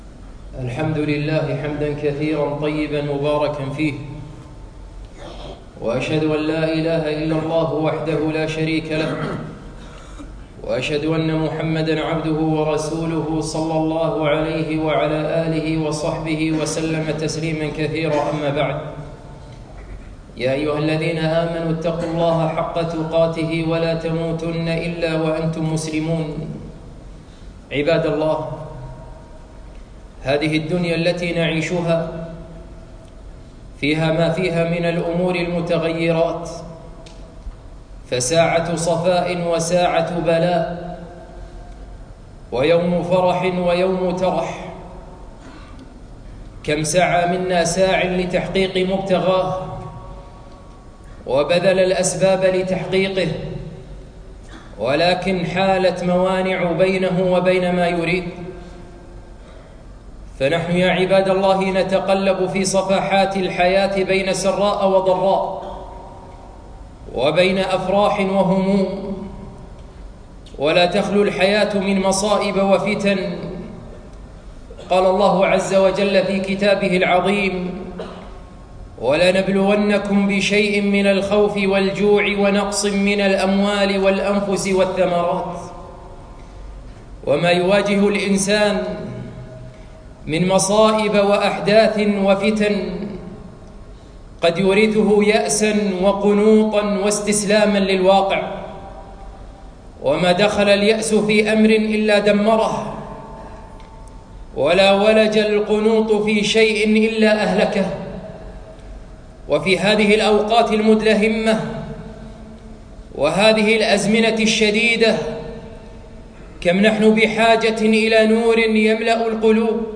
خطبة - التفاؤل